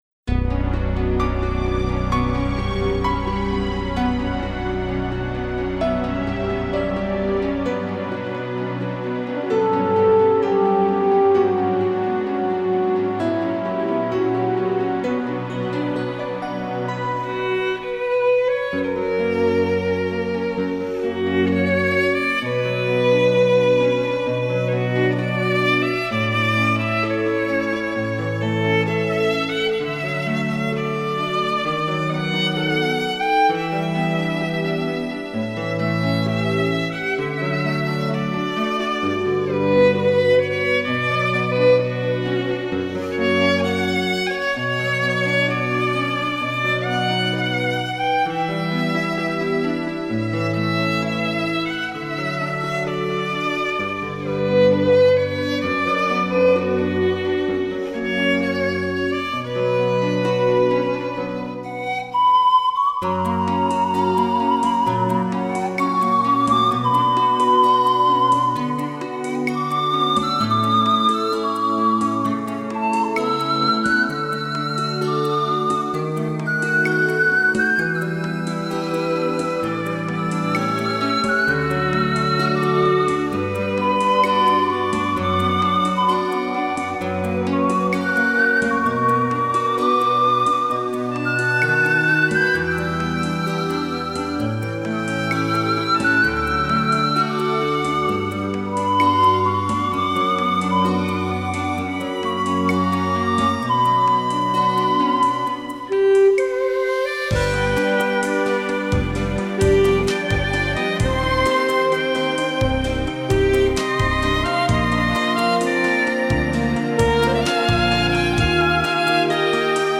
Замечательный романс